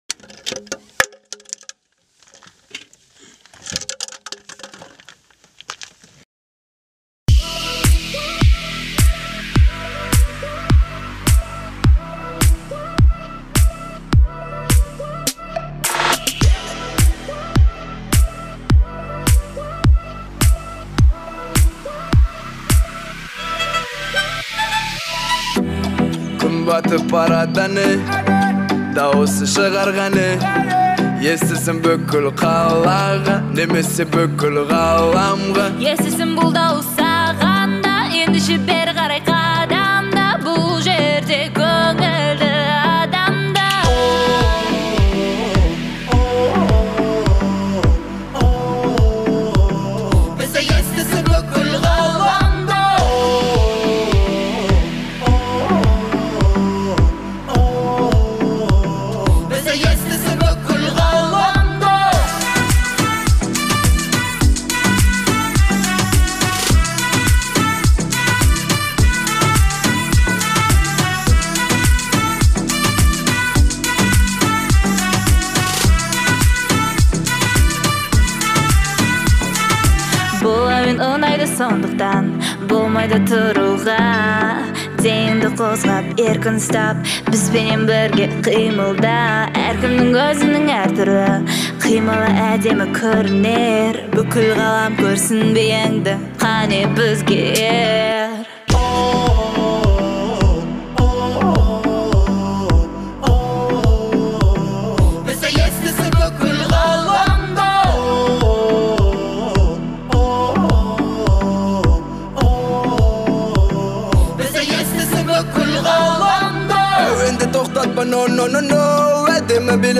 отличается мелодичностью и современными аранжировками